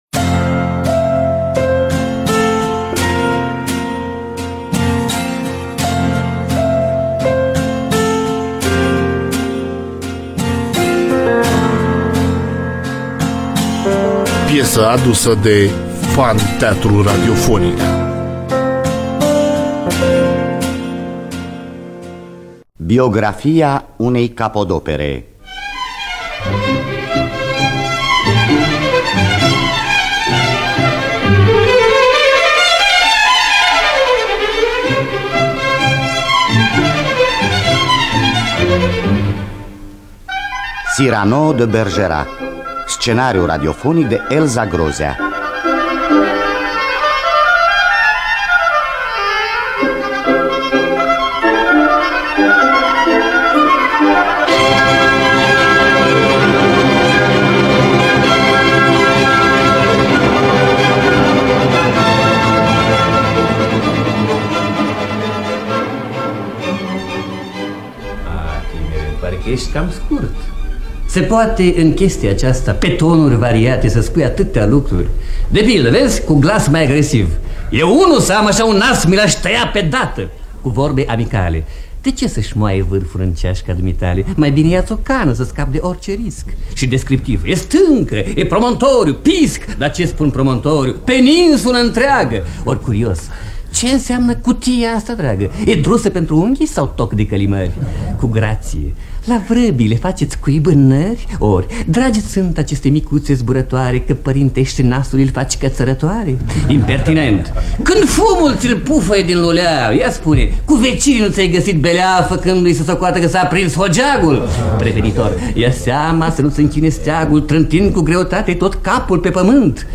Biografii, Memorii: Edmond Rostand – Cyrano de Bergerac (1980) – Teatru Radiofonic Online